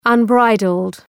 Προφορά
{ʌn’braıdld}